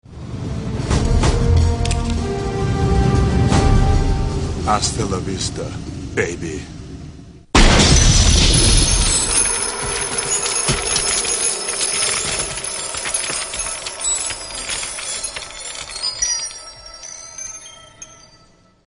Arnold Schwarzenegger as The Terminator in "Terminator 2" - 'Hasta la vista, baby'